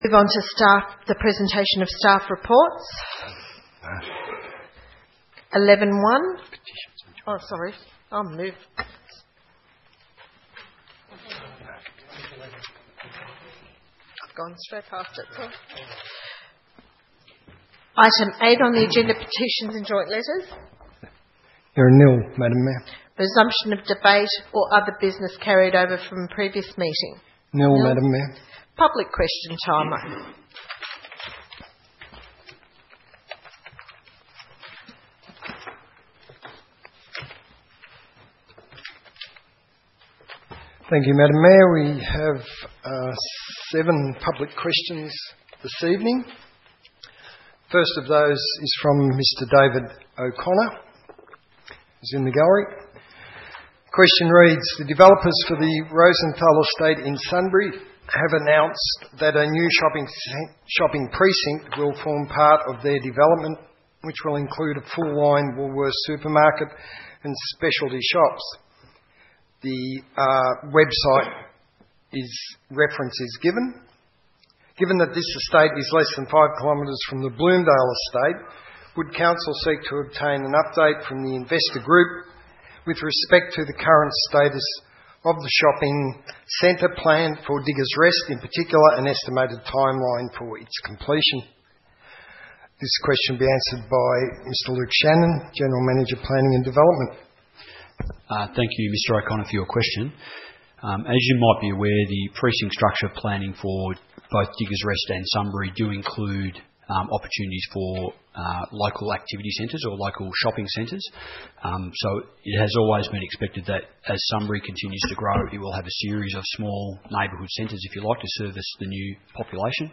26 May 2015 - Ordinary Council Meeting